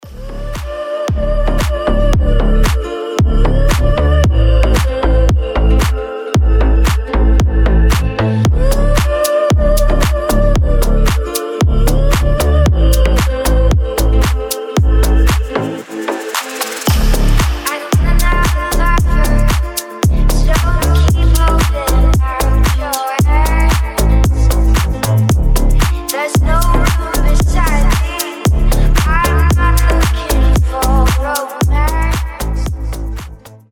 • Качество: 320, Stereo
гитара
deep house
женский голос
релакс